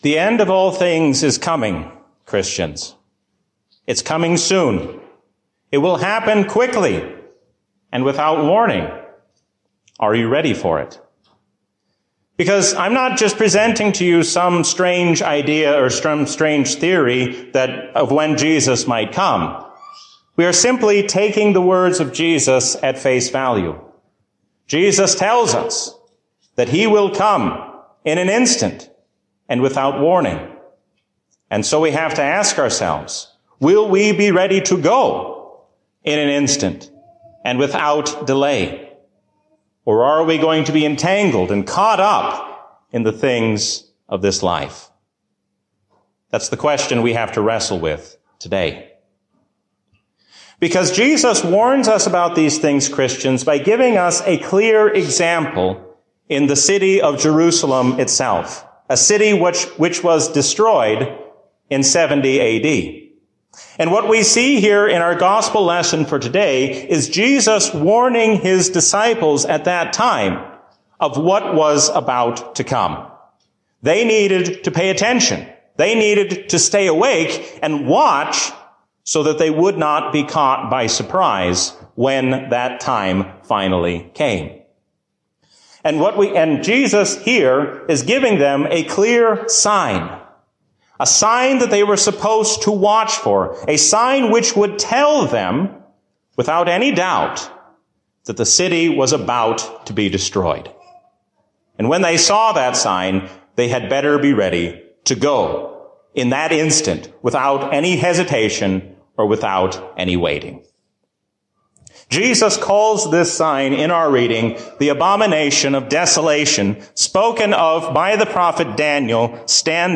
A sermon from the season "Trinity 2024." The New Jerusalem shows us what it will be like to be with God in glory forever.